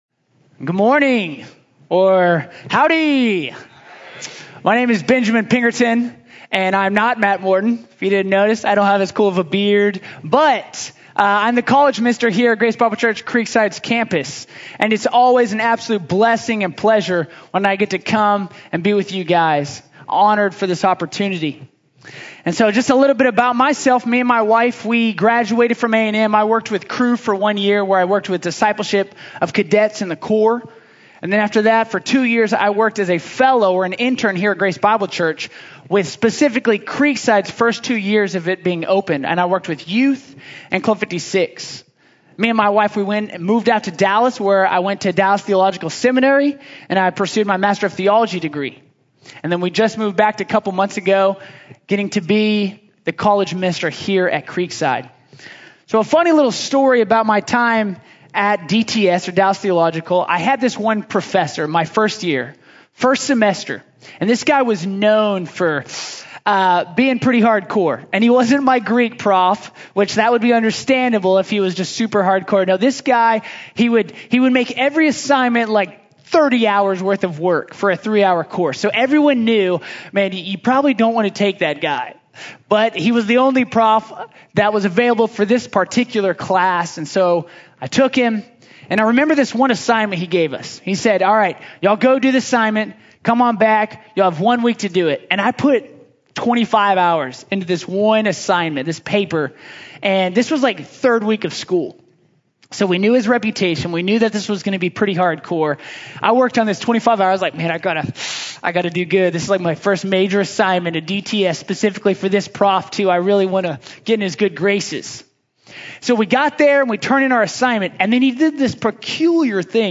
Life under the Gospel of Grace | Sermon | Grace Bible Church